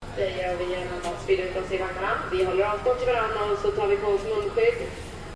Station Boarding Chatter 1
Station Boarding Chatter 1 is a free ambient sound effect available for download in MP3 format.
Station Boarding Chatter 1.mp3